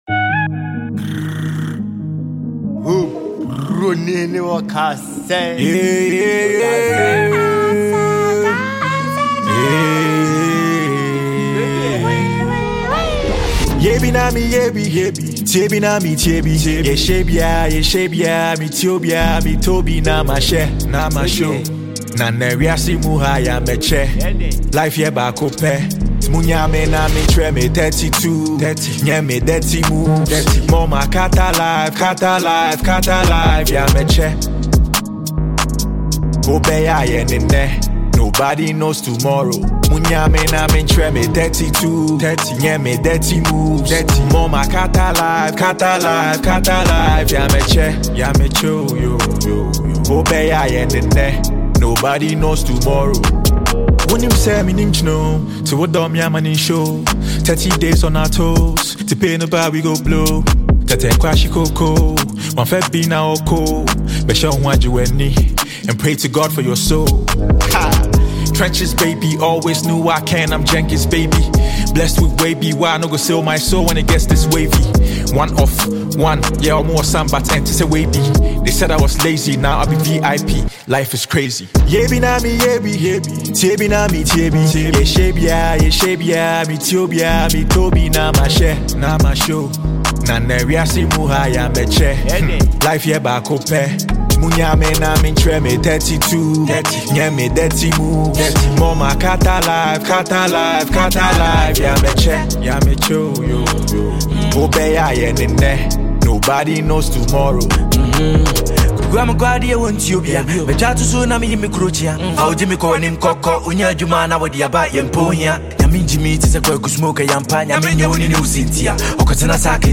Multi-talented Ghanaian rapper and songwriter